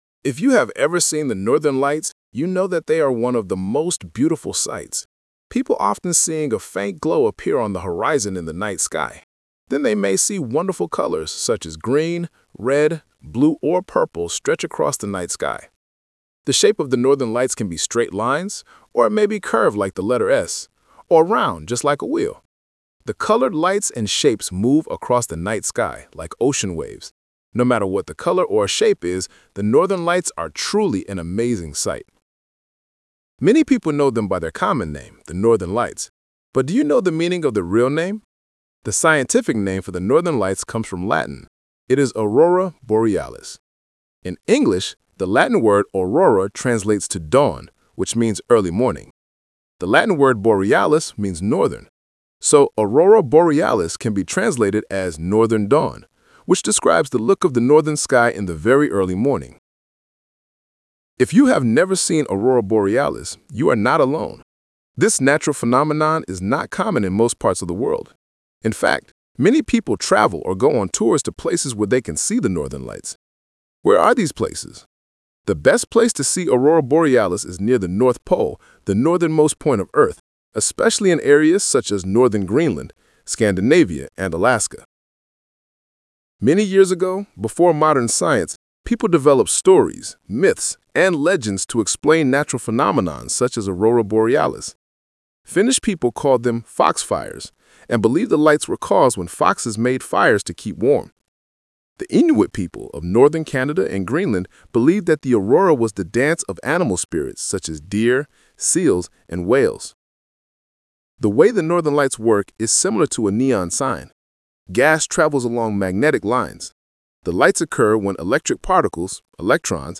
Talk/Lecture 3: Listen to a woman talking about northern lights.